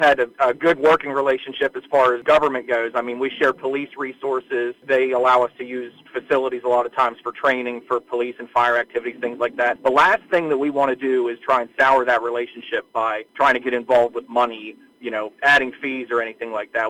That likely won’t be happening locally. WCBC reached out to Frostburg Mayor Todd Logsdon for his take on the student tax…